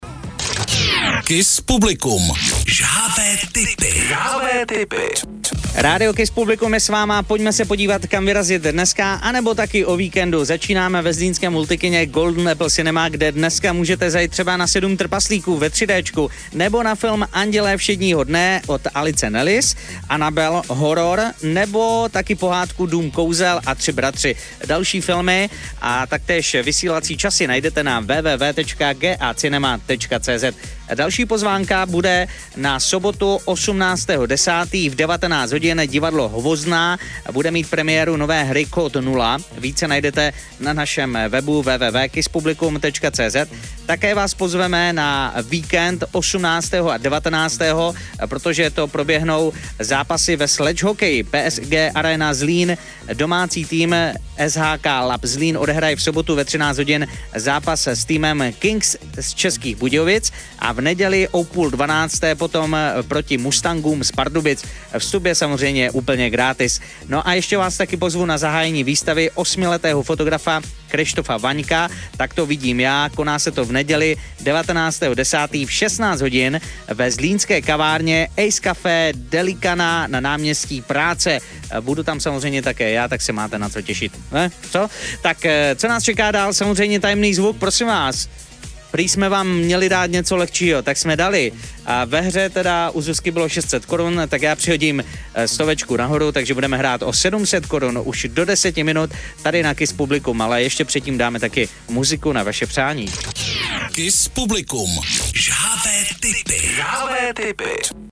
Upoutávky vysílané rádiem Kiss Publikum od úterý 14.10. na zápasy ČSHL